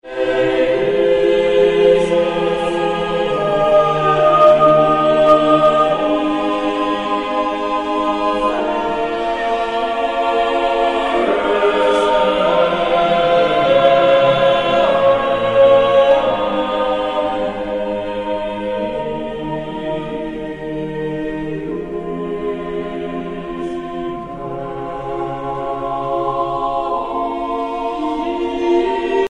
De akkoorden zwalken als het ware alle kanten op. Op het einde van bovenstaand schema zien we een uitwijking via een tussendominant [V] naar de zesde trap.
Dan komt er ook nog meer beweging door het gepuncteerde ritme in een dalend lijntje in de bas, (rode stippellijn).
Op dat punt lijkt er een echte cadens te komen, maar het afsluitende akkoord is een VI (zesde trap), een zogenaamd bedrieglijk slot dus.
morales-introitus-3a.mp3